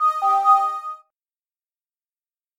Звук включения мобильного телефона